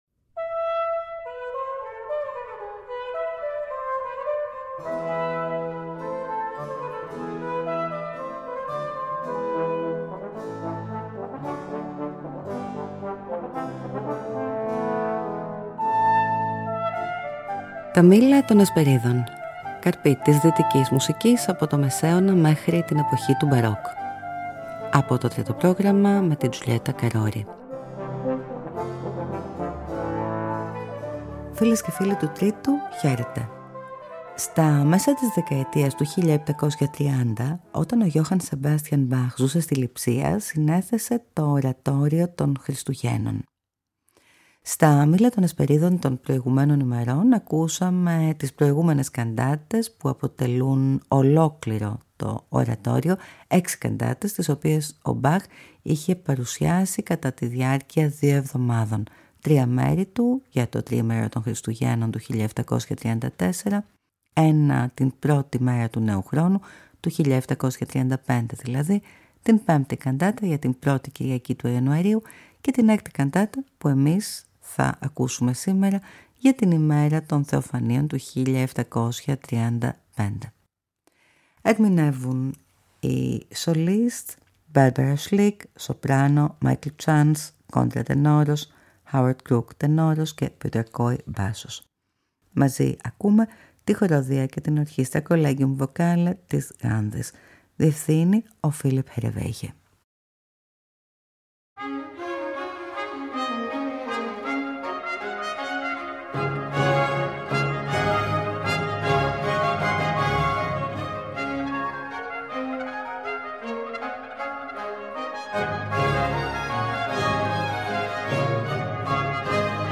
Καρποί της Δυτικής Μουσικής, από τον Μεσαίωνα μέχρι την εποχή του Μπαρόκ.